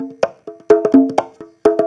Conga Loop 128 BPM (21).wav